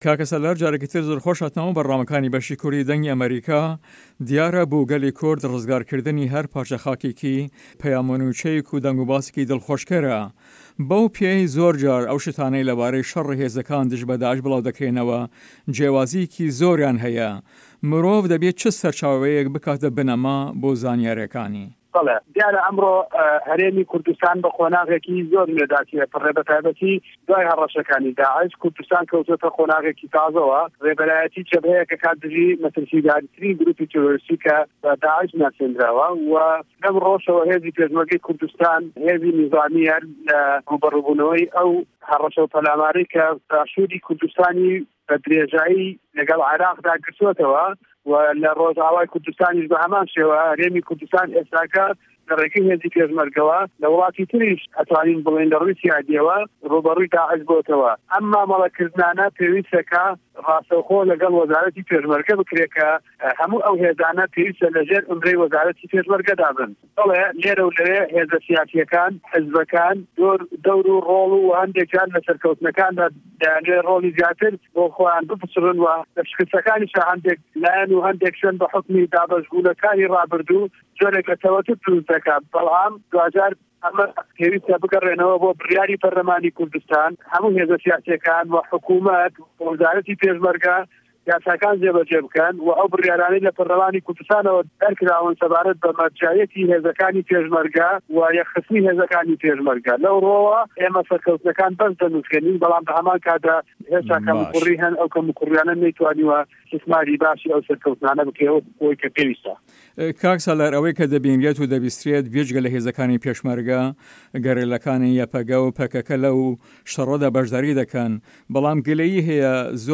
سالار مه‌حمود ئه‌ندام په‌رله‌مانی هه‌رێمی کوردستان له‌ هه‌ڤپه‌ێڤینێکدا له‌گه‌ڵ به‌شی کوردی ده‌نگی ئه‌مه‌ریکا ده‌ڵێت"دیاره‌ ئه‌مرۆ هه‌رێمی کوردستان به‌ قوناغێکی زۆر نوێدا تێده‌په‌رێ به‌ تاێبه‌تی دووای هه‌ره‌شه‌کانی داعش، کوردستان که‌وتوه‌ته‌ قوناغێکی تازه‌وه‌، رێبه‌راتی به‌ره‌یه‌ک ده‌کات دژ به‌ مه‌ترسیدارترین گروپی تیرۆریستی که‌ به‌ داعش ناسراوه‌ و هێزه‌کانی پێشمه‌رگه‌ رێبه‌رایه‌تی ئه‌و شه‌ره‌ ده‌کات، جا له‌به‌ر ئه‌وه‌ ئه‌م سه‌وداکردنانه پێویسته‌ راسته‌وخۆ له‌گه‌ڵ وه‌زاره‌تی پێشمه‌رگه‌دا بکرێت.